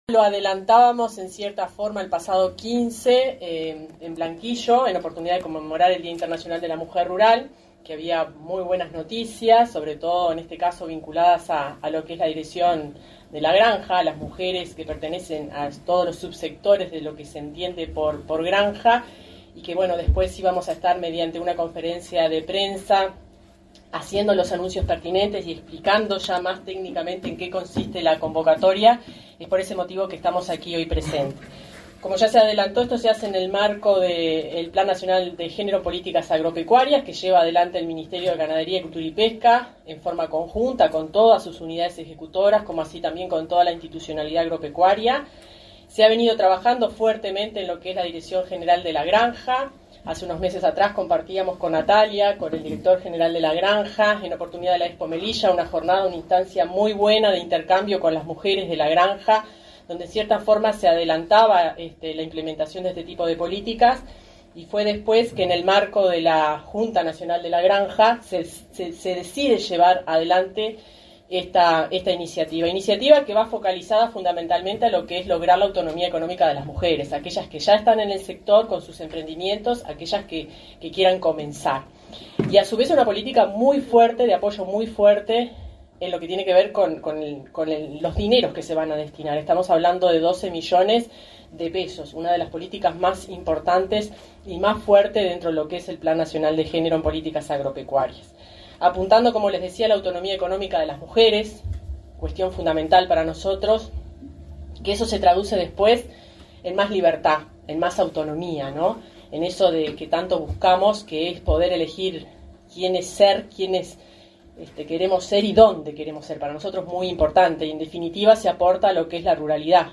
Conferencia de prensa por el lanzamiento de la convocatoria Mujeres de la Granja
La Dirección General de la Granja del Ministerio de Ganadería, Agricultura y Pesca (MGAP) lanzó, este 24 de octubre, la convocatoria Mujeres de la Granja, para el desarrollo productivo de mujeres de cualquier rubro productivo, mediante el acceso a herramientas y financiamiento. Participaron de la actividad el subsecretario del MGAP, Juan Ignacio Buffa; la directora general del ministerio, Fernanda Maldonado, y el director de la Granja, Nicolás Chiesa.